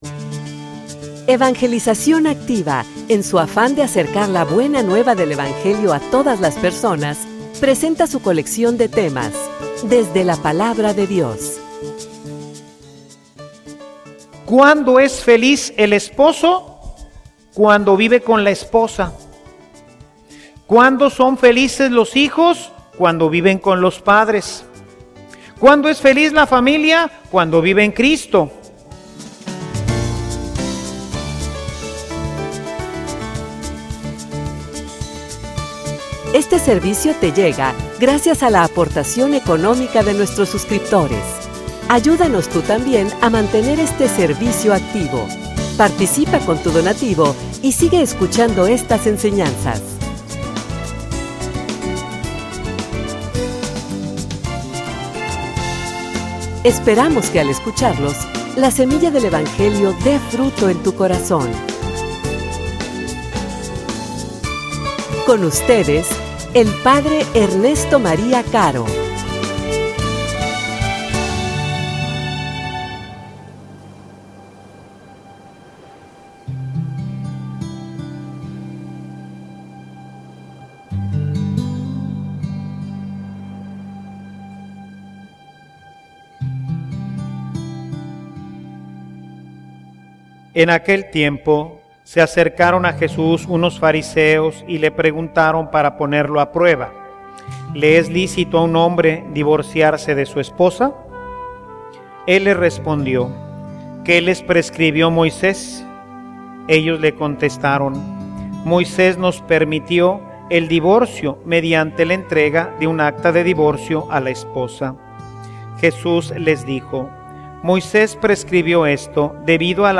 homilia_El_misterio_del_amor.mp3